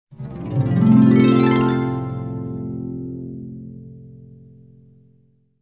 Звук воспоминаний, словно перелив струн